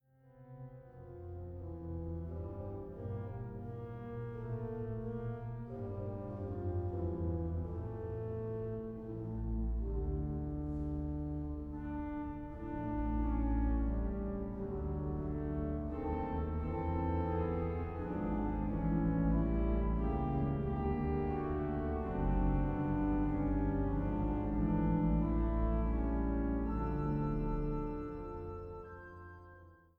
Vleugels-Orgel der Schloßkirche Chemnitz